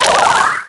mystic_atk_01.ogg